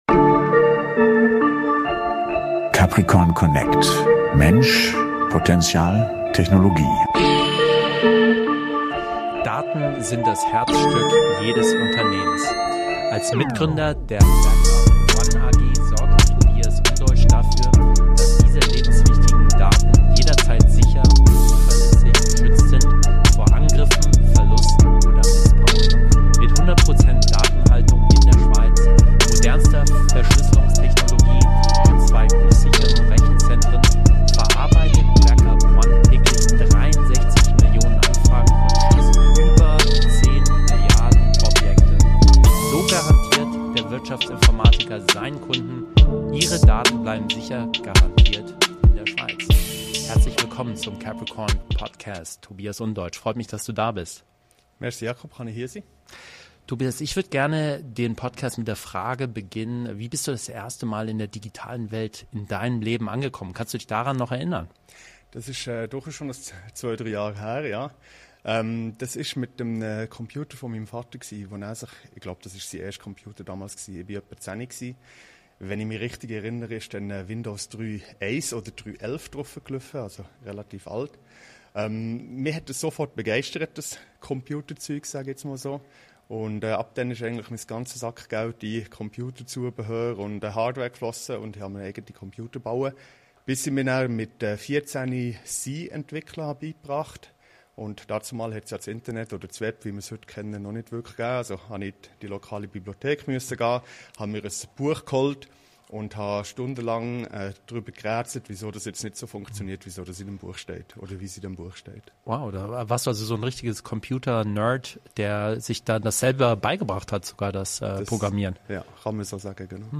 Ein inspirierendes Gespräch über Unternehmertum, Fehlerkultur, technologische Innovation und die Zukunft der Arbeit.